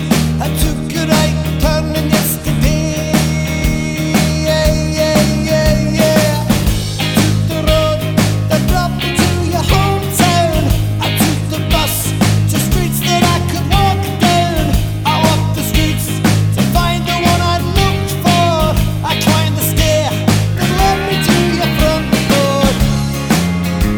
With Harmony Pop (1980s) 3:31 Buy £1.50